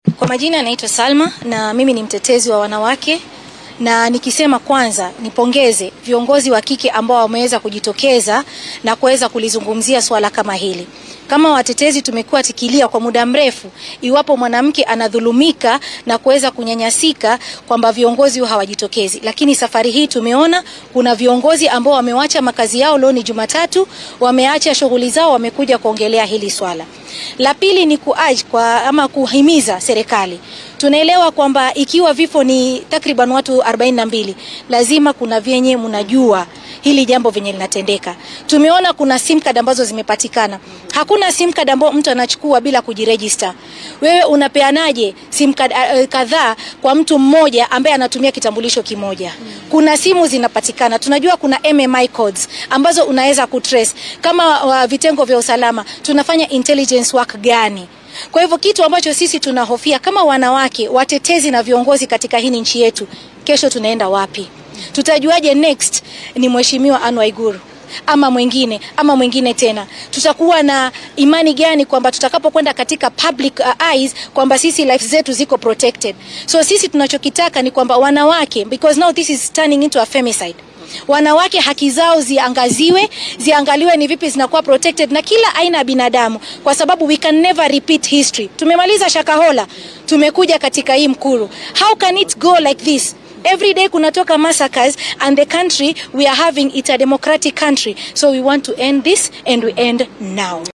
Waxay cambareeyee dilalka loo gaystay haweenka kenyaanka ee meydadkooda la helay. Mid ka mid ah shakhsiyaadka u dooda xuquuqda haweenka oo la hadashay warbaahinta ayaa sheegtay in loo baahan yahay in la xaqiijiyo badqabka dumarka, lana soo afjaro dhibaateynta loo gaysanayo.